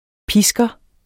Udtale [ ˈpisgʌ ]